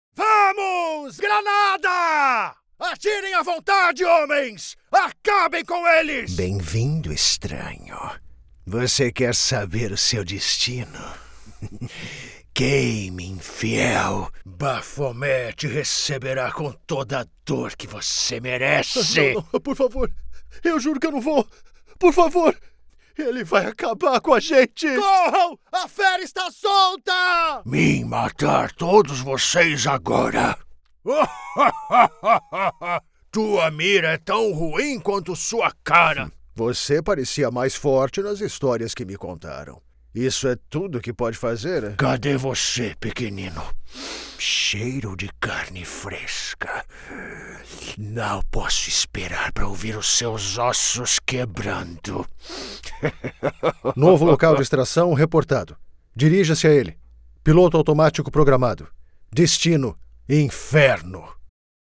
Videojuegos
Mi tono es conversacional, pero también puedo hacer la voz de un adulto joven y de una persona mayor.